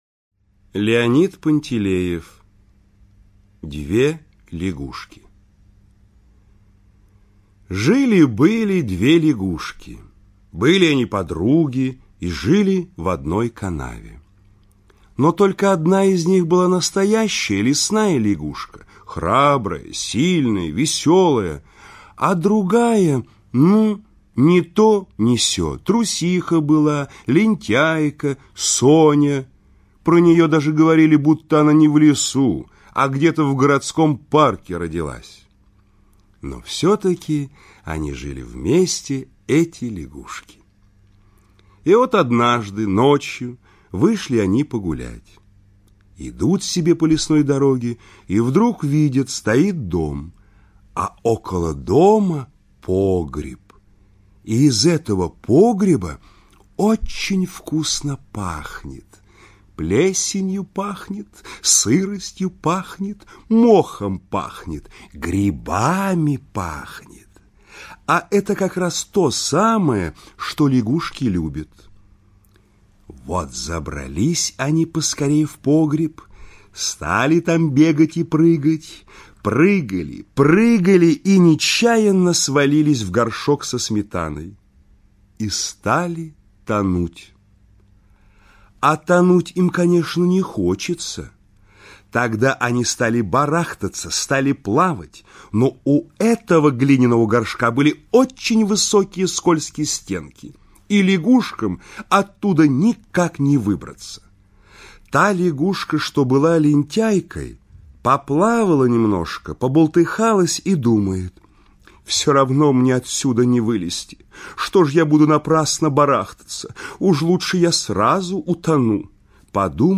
Две лягушки - аудио рассказ Пантелеева Л. Рассказ про двух лягушек, которые случайно попали в горшок со сметаной и никак не могли выбраться.